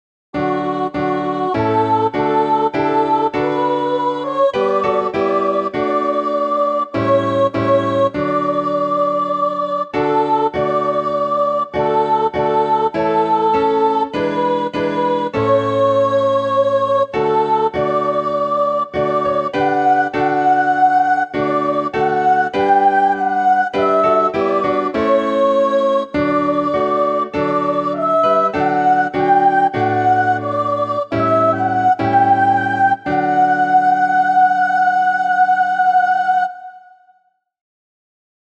A soprano descant for the final verse of Redeemer of Israel. Not for the faint of heart: Sopranos sing up to a high G.
Voicing/Instrumentation: SATB We also have other 18 arrangements of " Redeemer of Israel ".